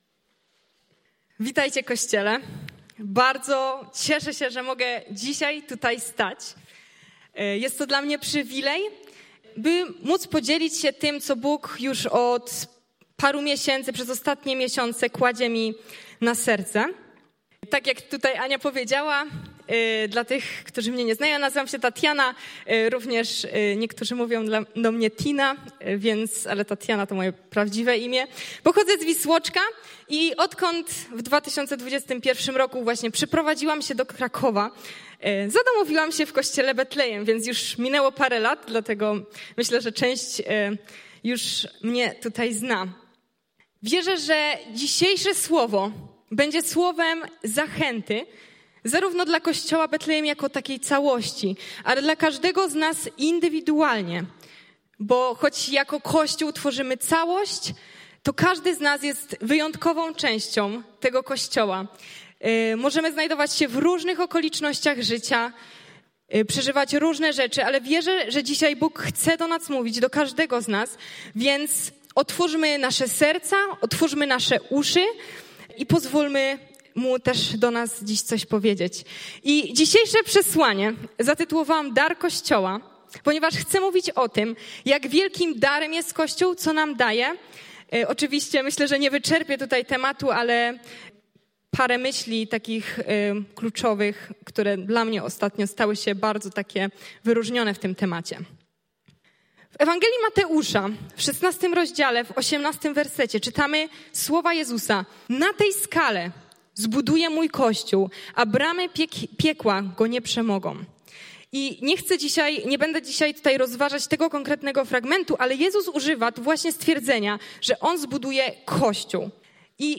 Pytania do przemyślenia po kazaniu: